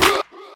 Vox
Huaah.wav